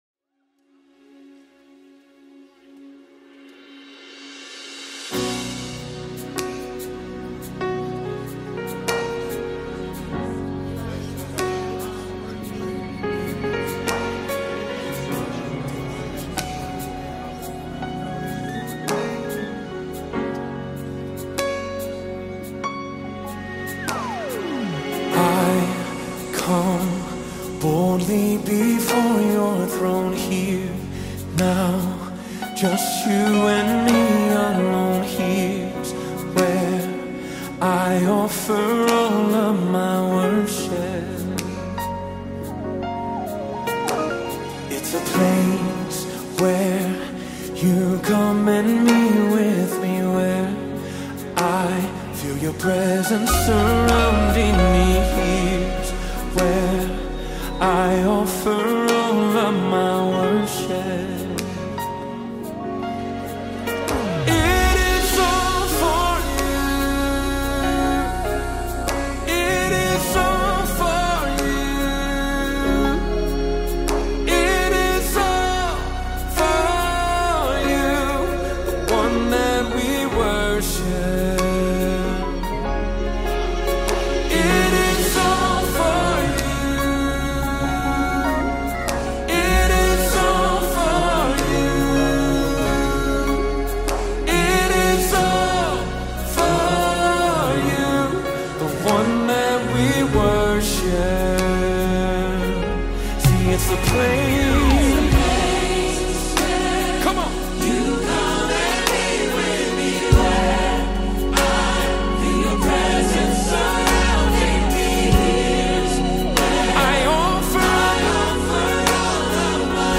With its gentle melody and soaring vocals